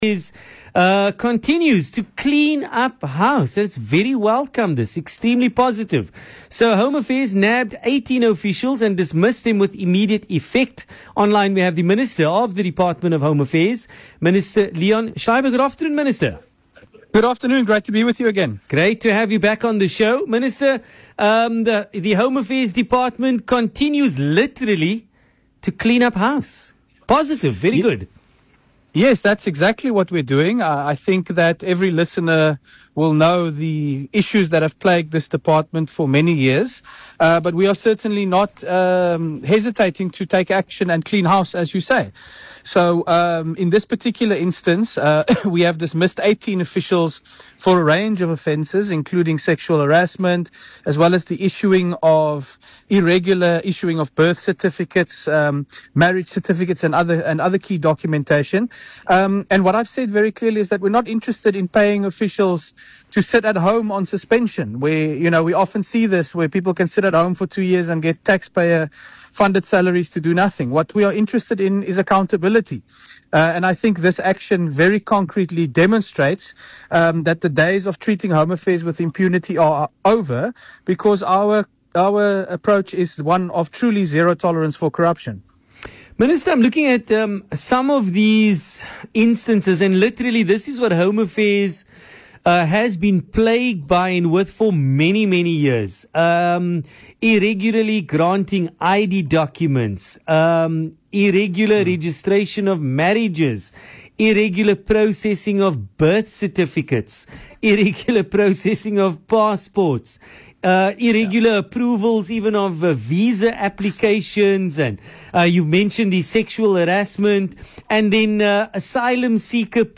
Speaking on VOC’s PM Drive show on Tuesday, the Minister of the Department of Home Affairs, Dr. Leon Schreiber, said the move calls for accountability.